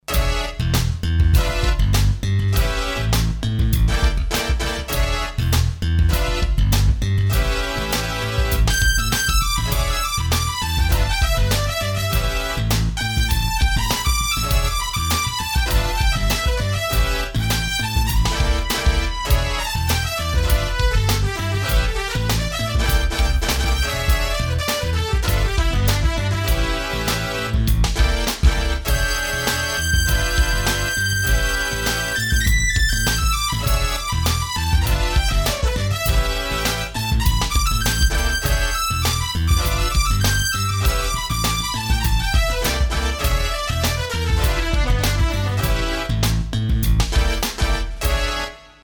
Buttondemo.mp3 shows off what you can do if you're lazy and perform your whole left hand part with the stock-chords white buttons.